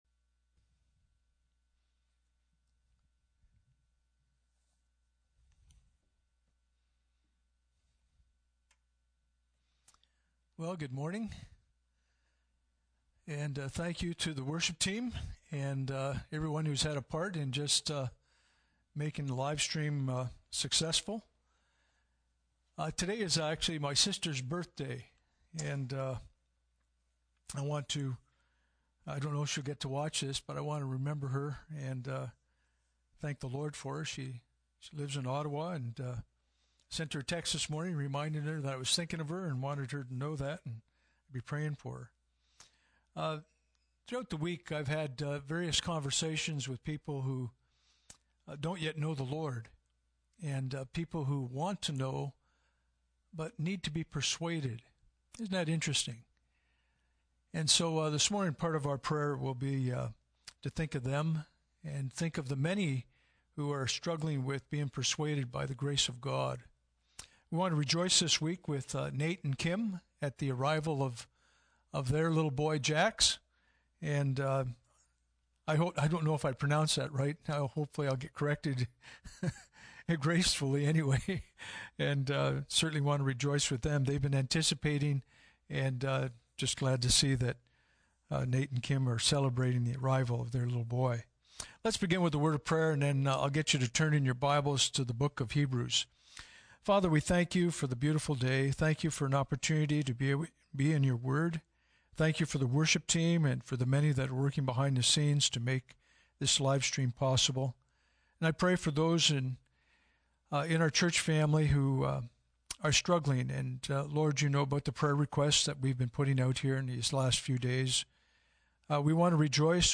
Passage: 2 Kings 15:37-16:4 Service Type: Sunday Morning « Communion Magnification Of Faith In The Midst Of Fear